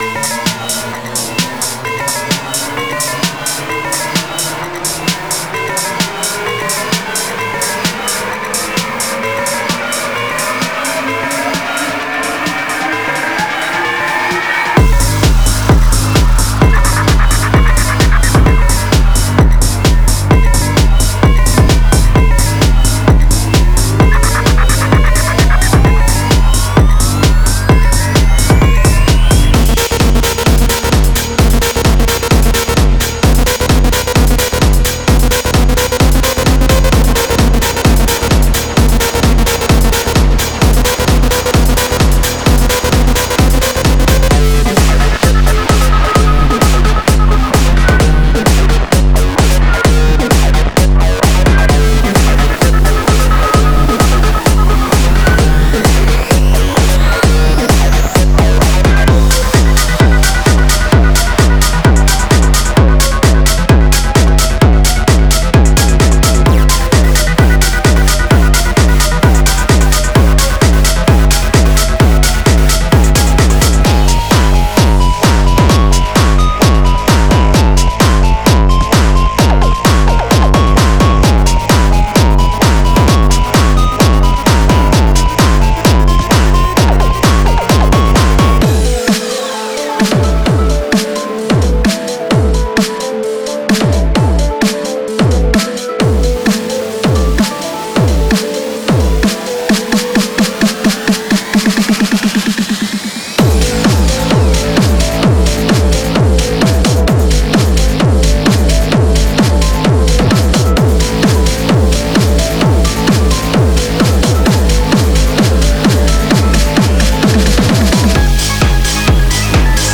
デモサウンドはコチラ↓
Genre:Industrial Techno